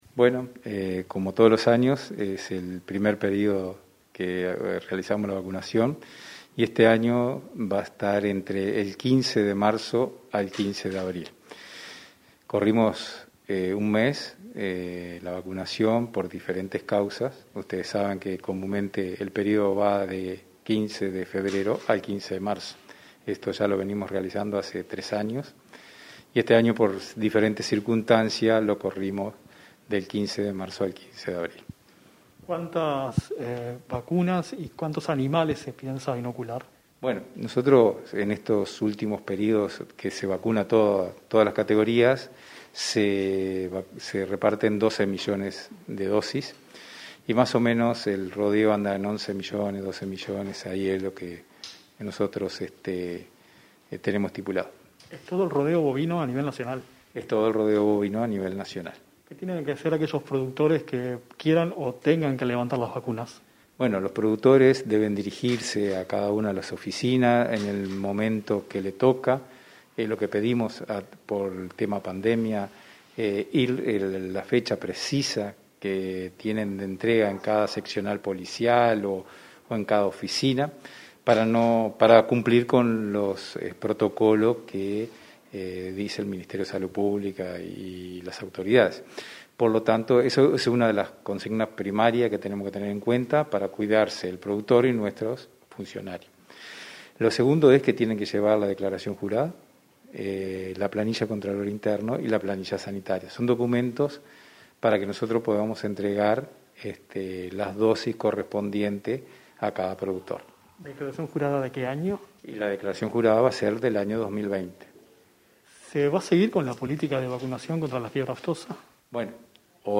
Entrevista al director de Servicios Ganaderos del Ministerio de Ganadería, Eduardo Barre